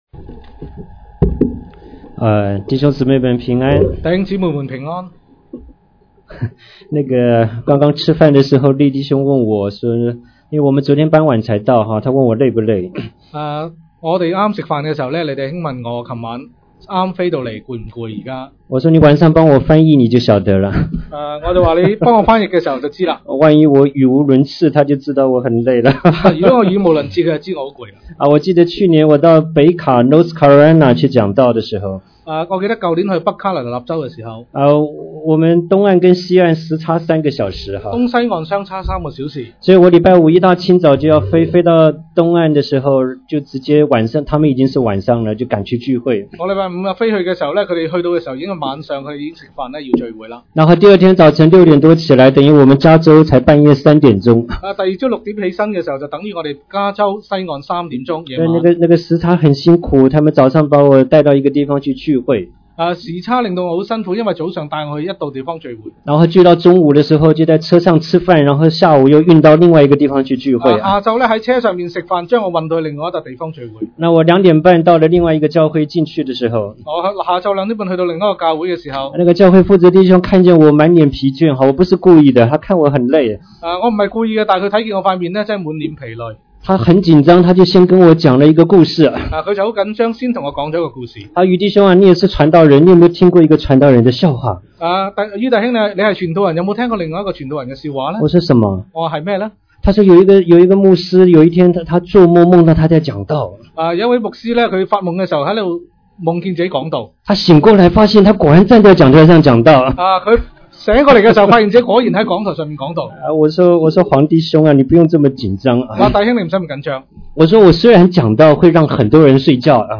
特別聚會
北角聚會所 「彼此相愛」確實是老生常談的題目，但如何具體落實到日常信徒相交生活，確實是不容易。